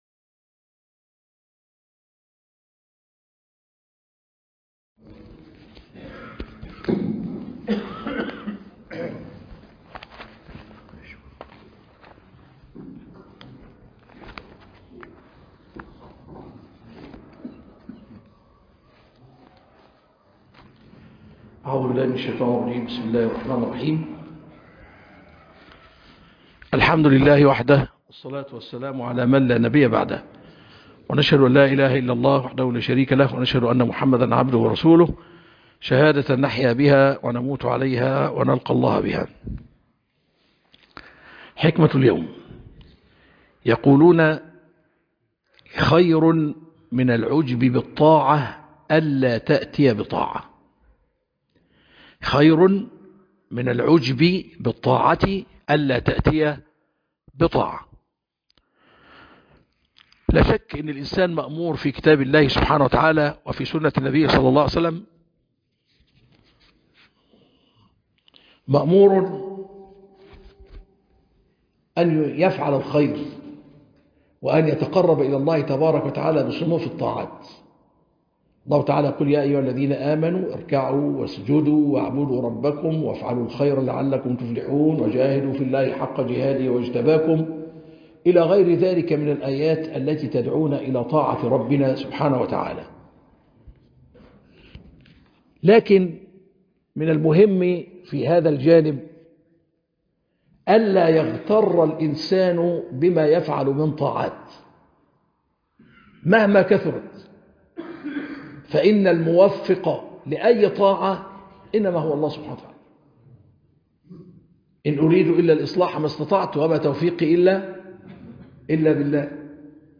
خاطرة حول (خير من العجب بالطاعة ألا تاتى بطاعة) - الشيخ طلعت عفيفى